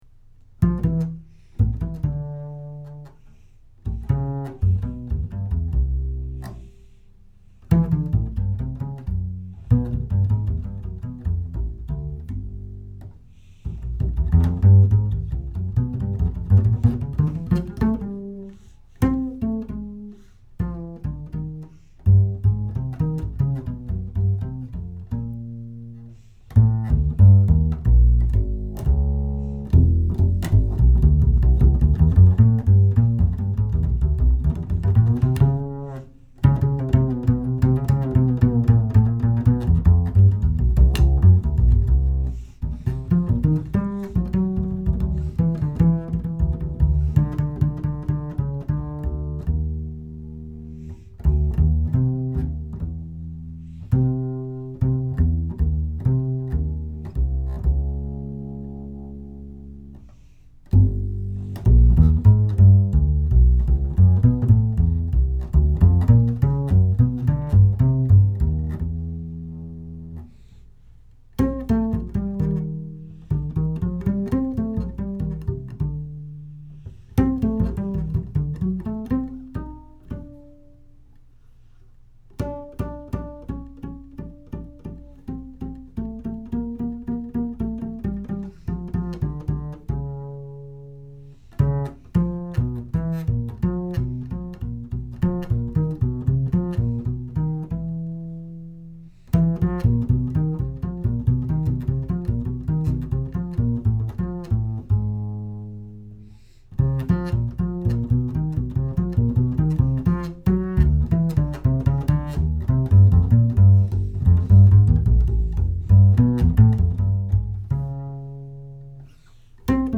In preparation for my lecture recital at Eastman on May 5th, here's an improvised solo bass piece called "21st Day."